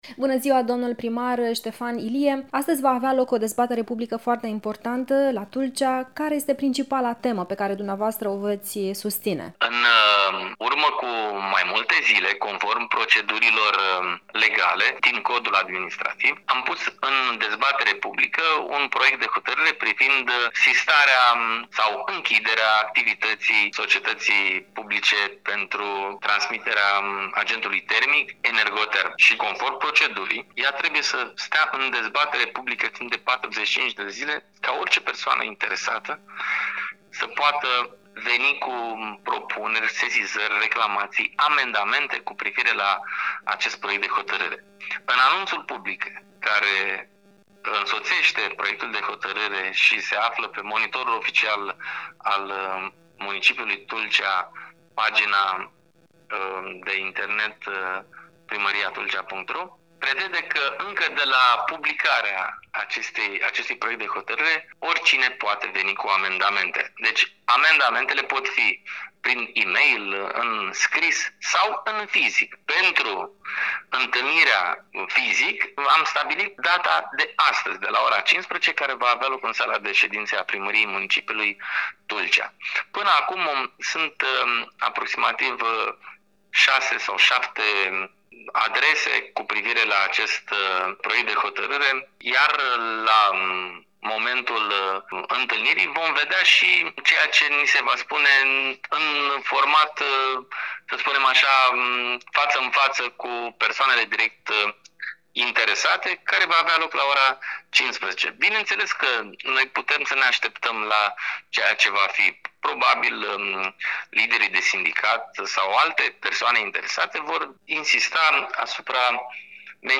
Interviu cu primarul Ștefan Ilie despre situația Energoterm